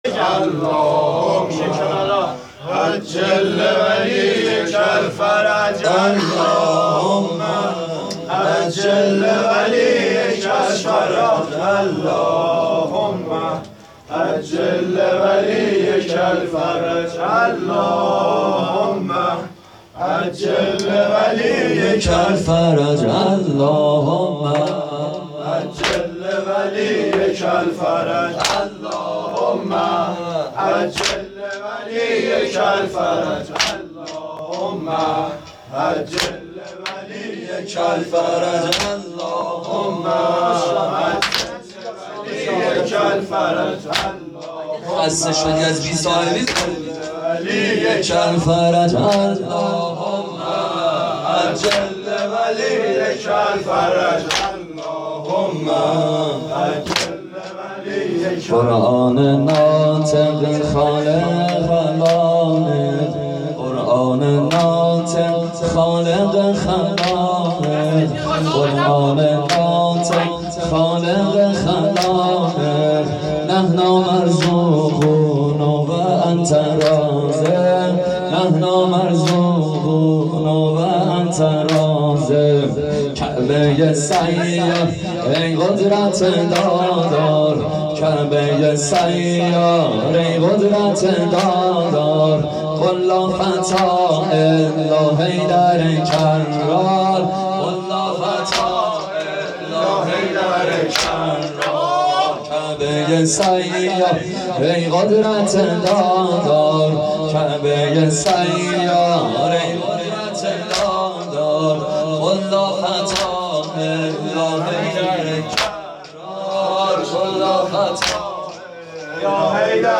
خیمه گاه - هیئت المهدی(عج) - واحد شهادت امام علی ع
هیئت المهدی(عج)